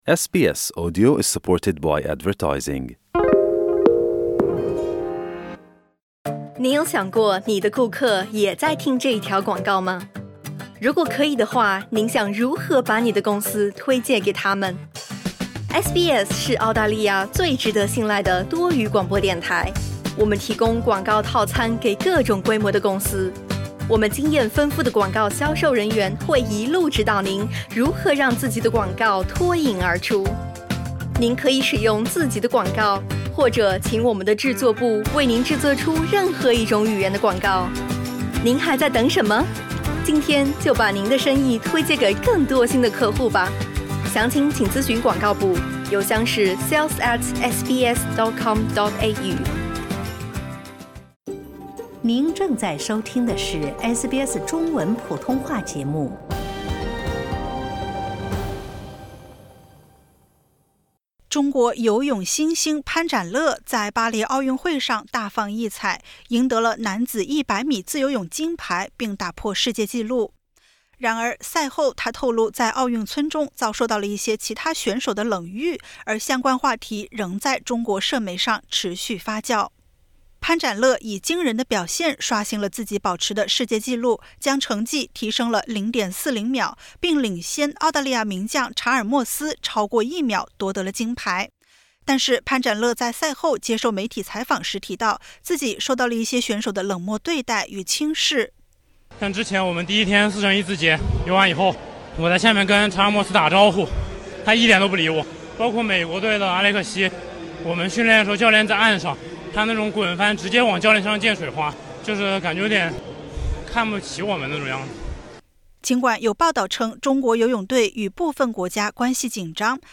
中国游泳新星潘展乐在巴黎奥运会上大放异彩，赢得男子100米自由泳金牌并打破世界纪录。然而，赛后他透露，在奥运村中遭受到了一些其他选手的冷遇，而相关话题仍在中国社媒上持续发酵。点击 ▶ 收听完整报道。